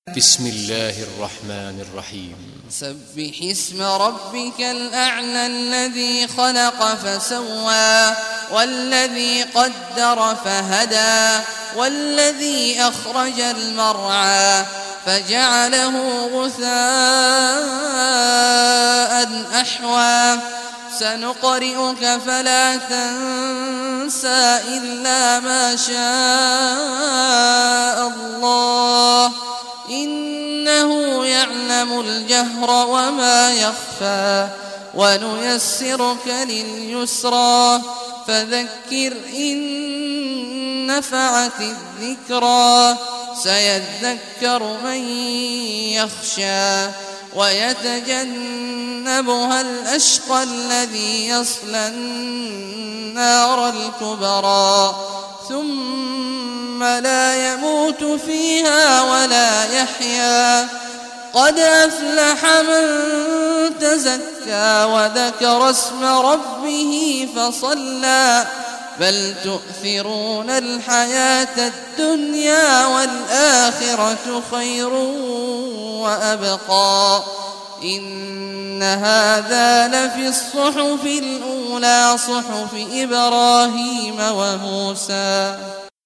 Surah Al Ala Recitation by Sheikh Awad al Juhany
Surah Al Ala, listen or play online mp3 tilawat / recitation in Arabic in the beautiful voice of Sheikh Abdullah Awad Al Juhany.